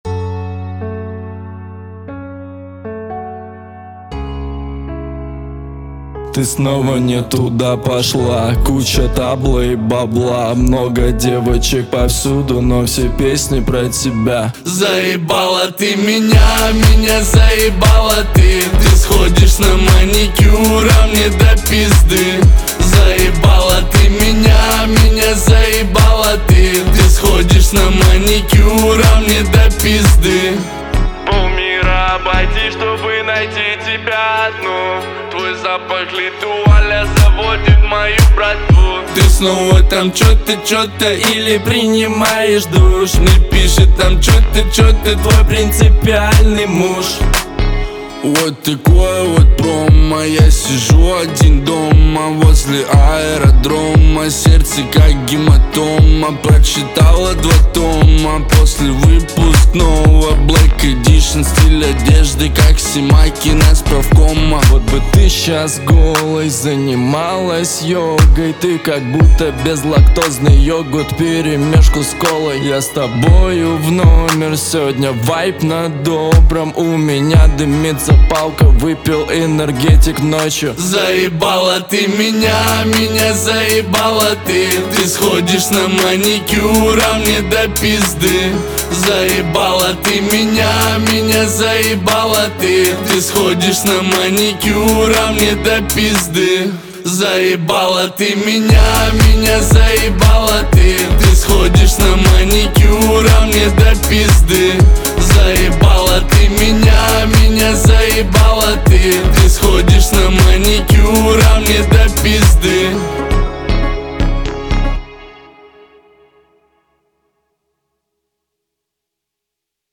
Качество: 320 kbps, stereo
Рэп, Новинки музыки в пятницу